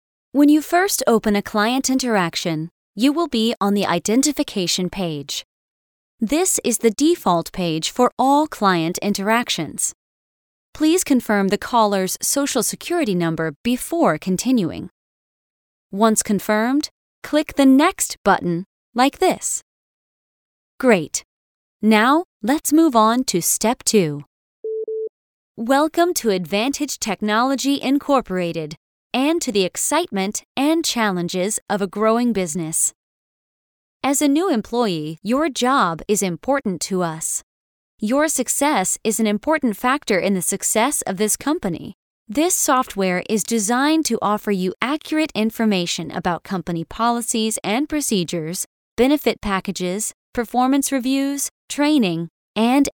Female Voice Over, Dan Wachs Talent Agency.
Young Mom, Best Friend, Warm & Caring.
eLearning